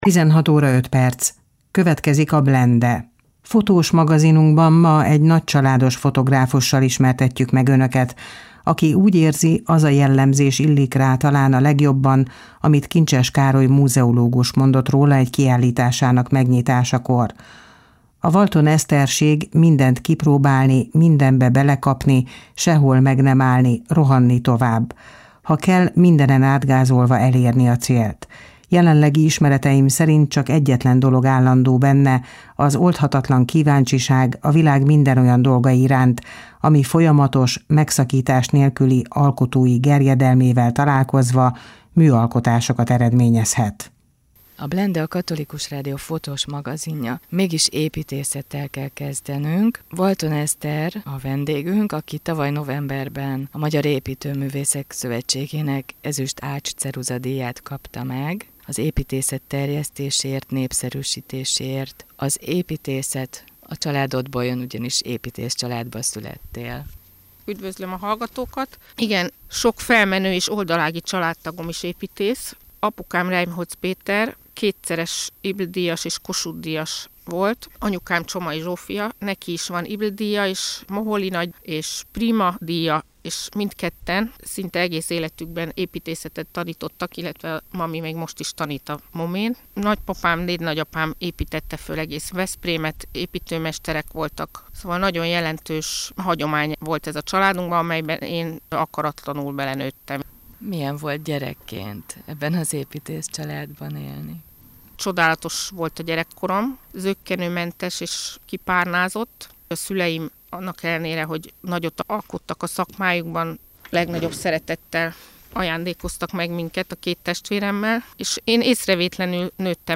27_riport_a_blende_radioban.mp3